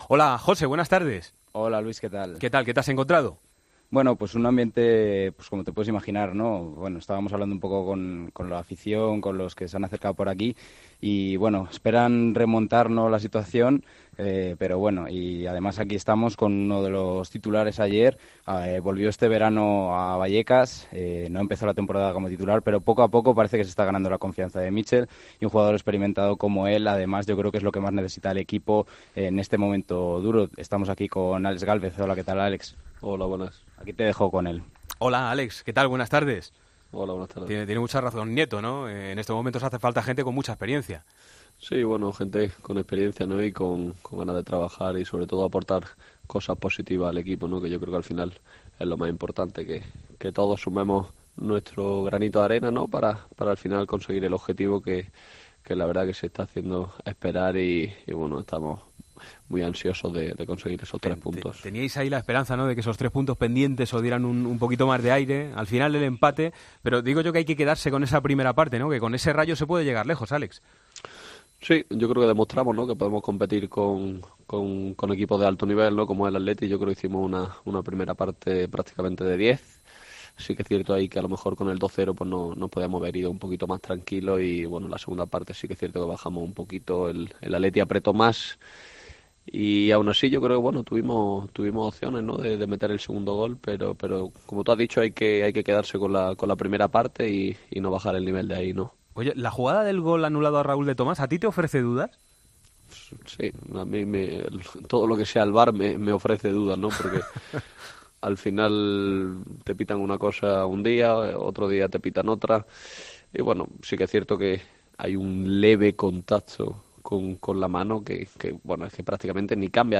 "Los resultados no están siendo favorables, pero la imagen es positiva", señaló el defensa rayista en los micrófonos de Deportes COPE.